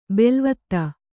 ♪ belvatta